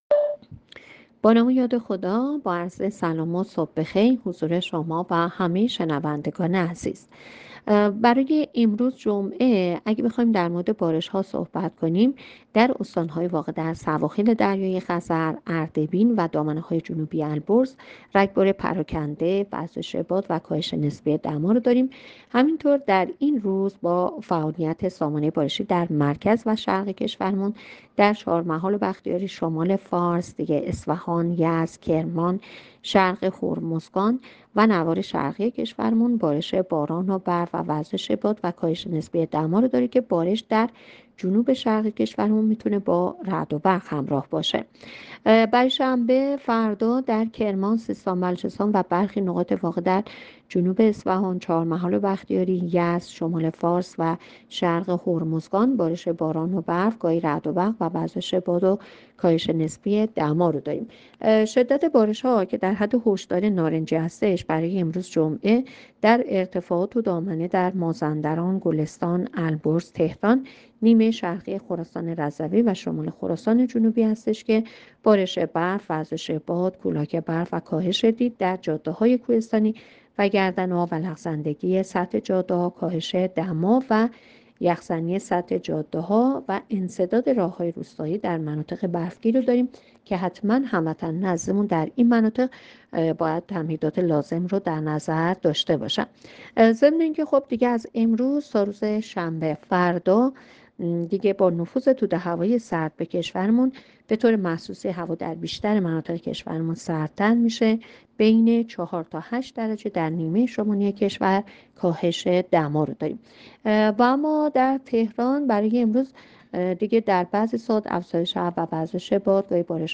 گزارش رادیو اینترنتی پایگاه‌ خبری از آخرین وضعیت آب‌وهوای ۲۸ دی؛